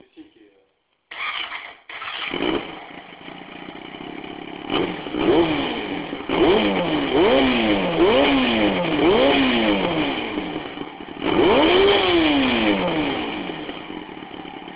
debridage et syncro carbu NEW [ SON DU POT ]